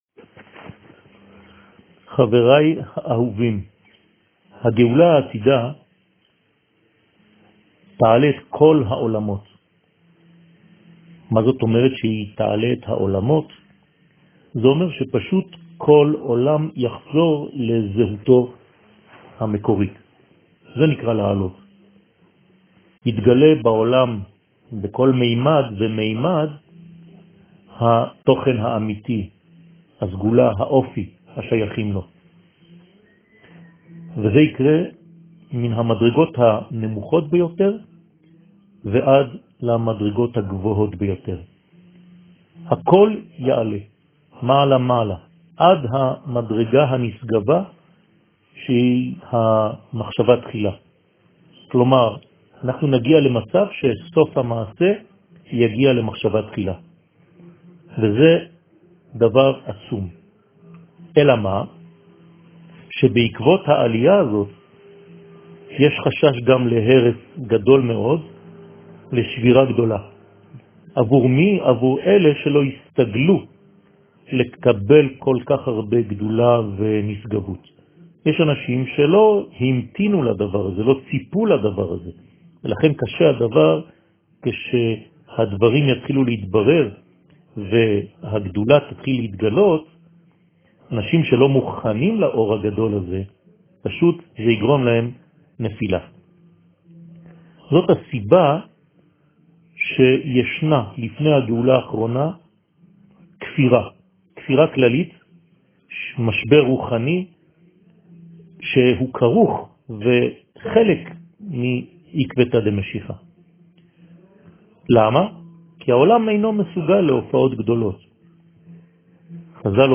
שיעור מ 08 יולי 2020